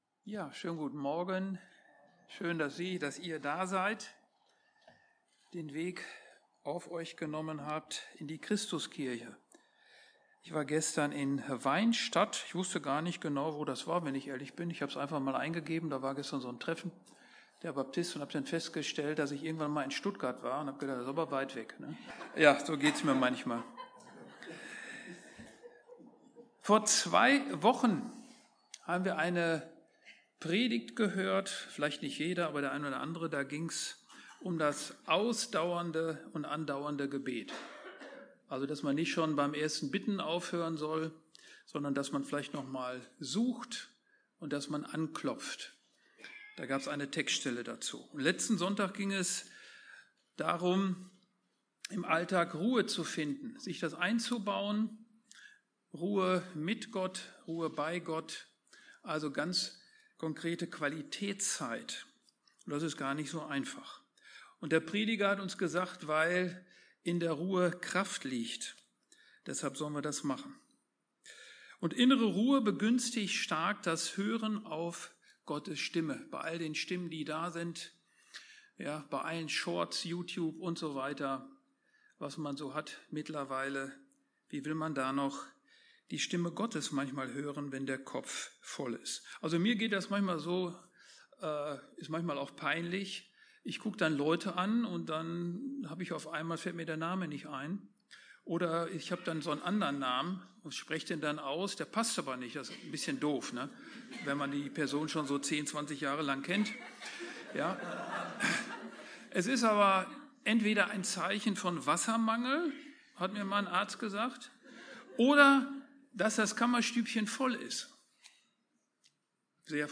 Predigten – Christuskirche Bensheim-Auerbach